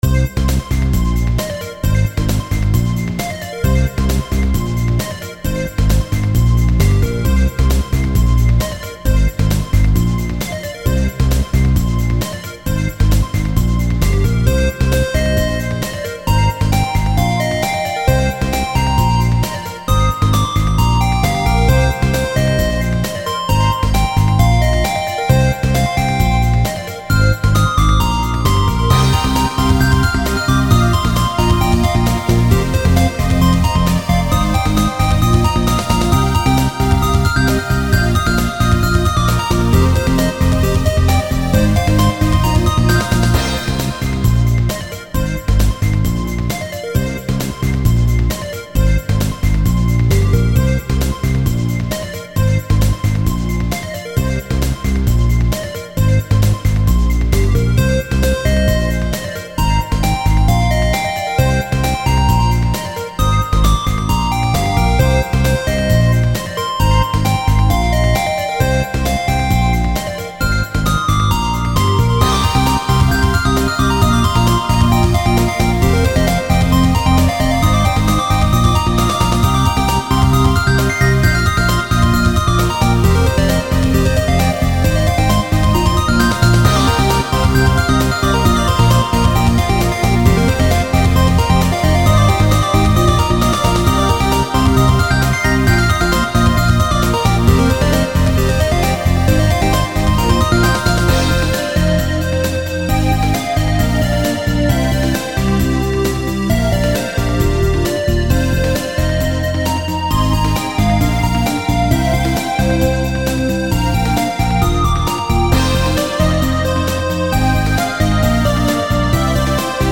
8 bit
megaman style chiptune